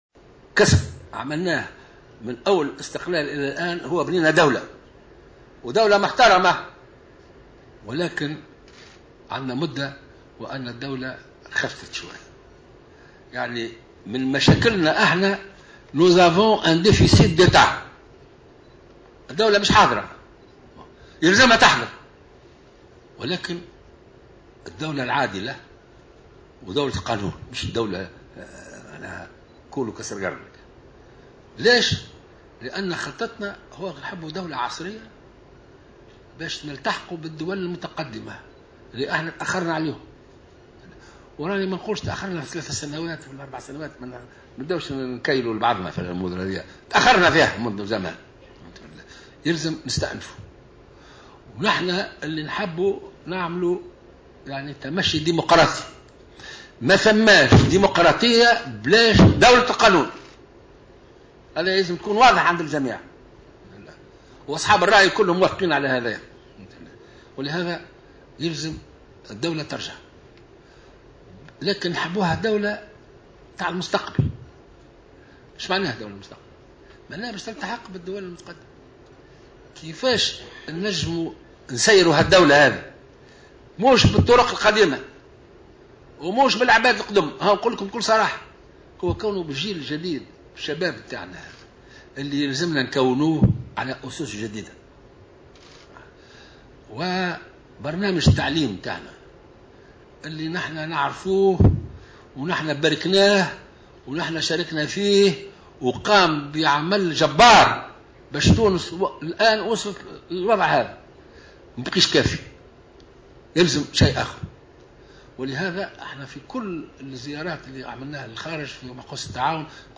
وقال قائد السبسي في كلمة ألقاها بمناسبة افتتاحه ملتقى للمعهد التونسي للدراسات الإستراتيجية، إنه على الدولة القيام بمهمتها على أكمل وجه تجاه ما يحدث في الجنوب، في إشارة إلى مظاهرات شعبية متواصلة في الجنوب التونسي للاحتجاج ضد الفساد في قطاع الطاقة.